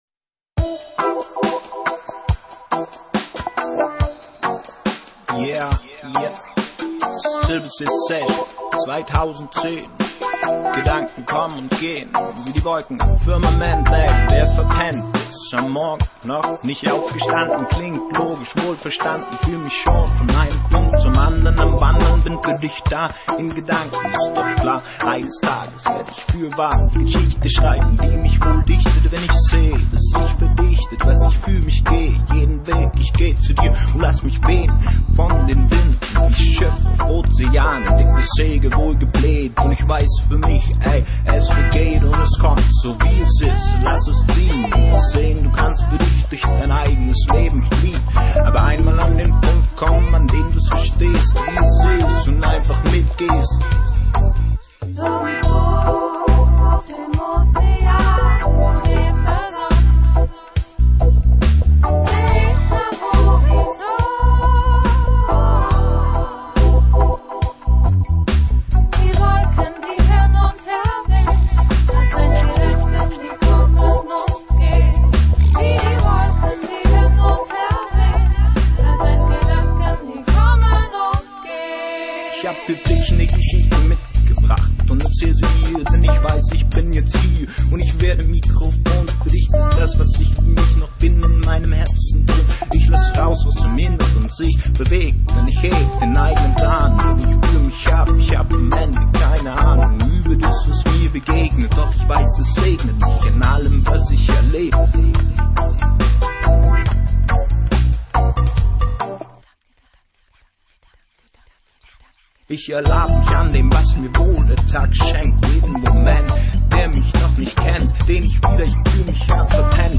world
dub, reggae, hip hop and world music from the heart
Work type Music